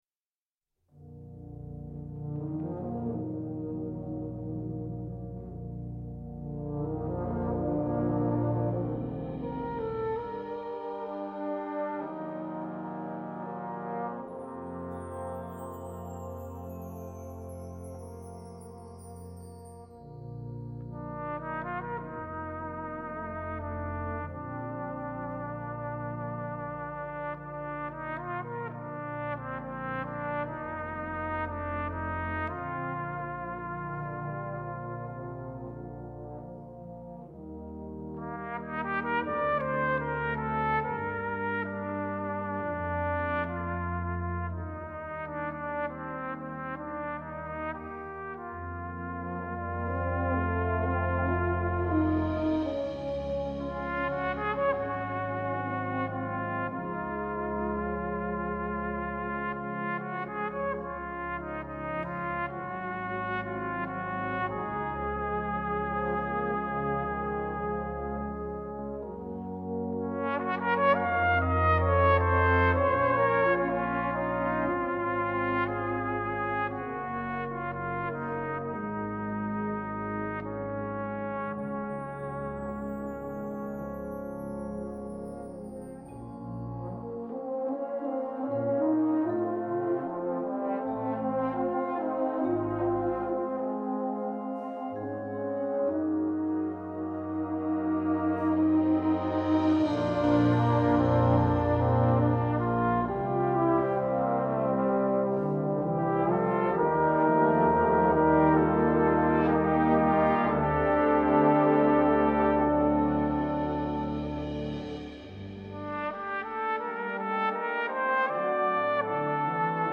Brass Band
Solo & Brass Band